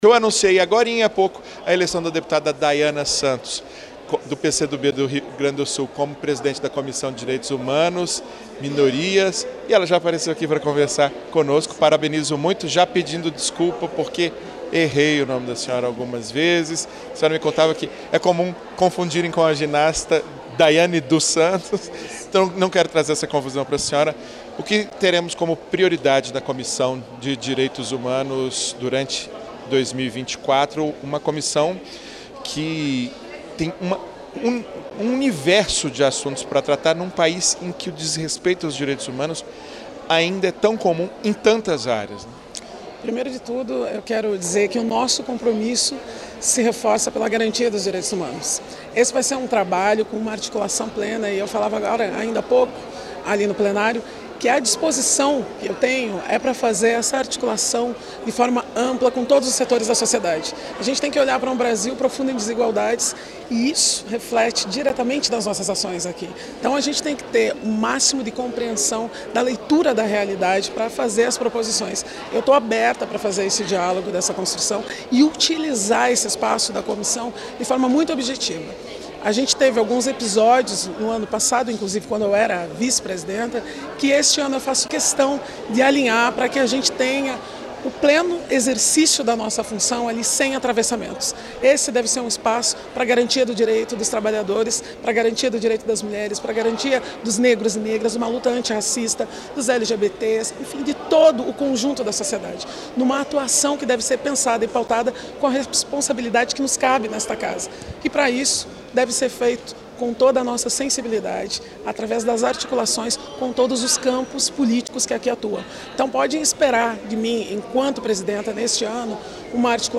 A deputada Daiana Santos (PCdoB-RS) é a nova presidente da Comissão de Direitos Humanos, Minorias e Igualdade Racial da Câmara. Eleita nesta quarta-feira (6) para comandar o colegiado neste ano, ela conversou com a equipe da Rádio e TV Câmara sobre como pretende conduzir os trabalhos, especialmente na busca pela redução das desigualdades no país.